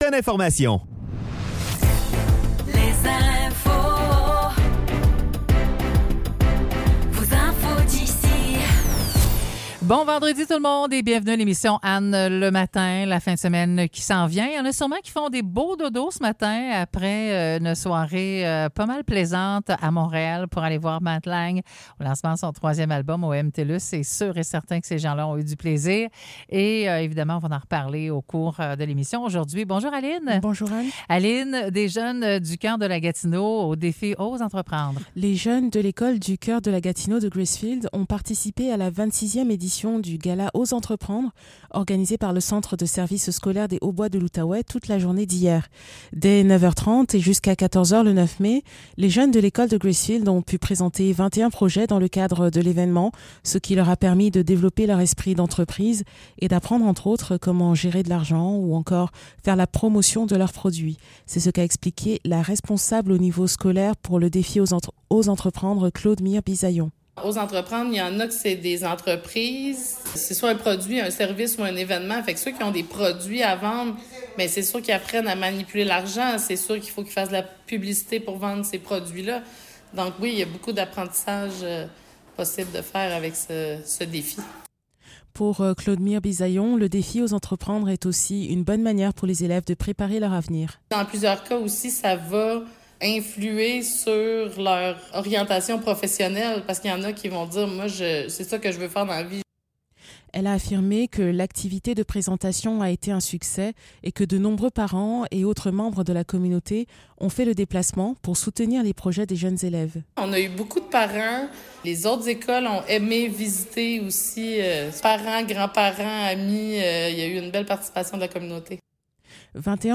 Nouvelles locales - 10 mai 2024 - 9 h